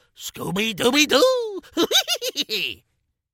scooby-doo-laugh_21020.mp3